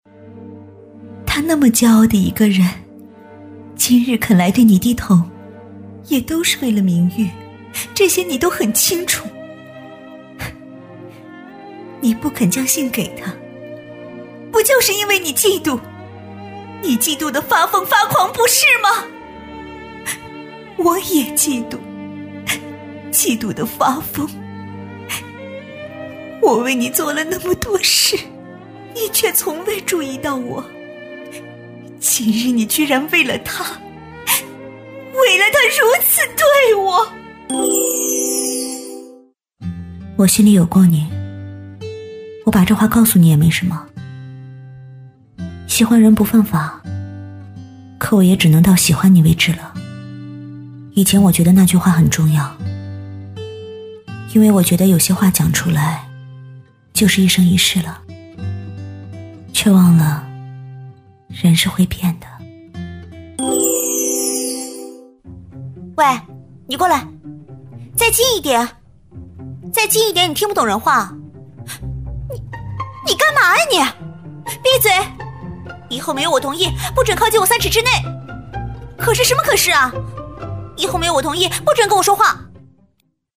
01女127系列-温馨提示-停车场（温馨甜美）-飞乐传媒官网